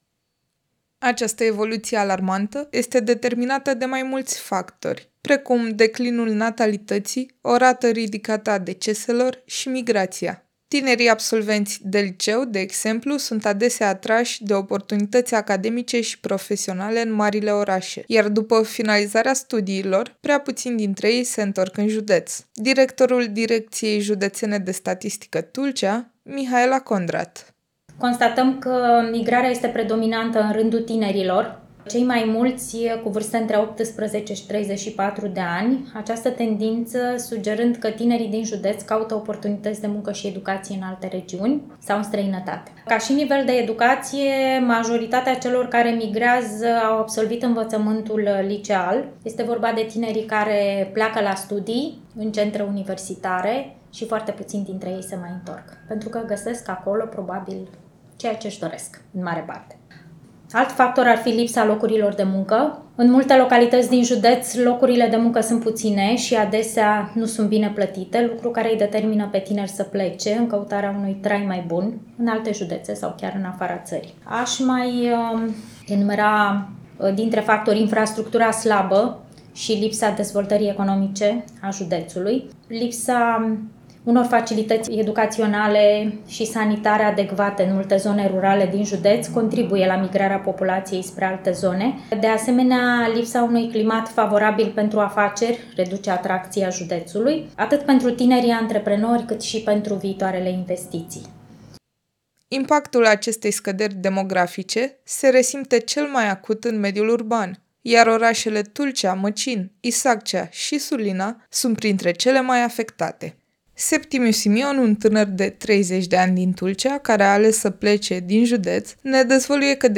Cauzele depopulării masive a județului Tulcea din perspectiva tinerilor, într-un reportaj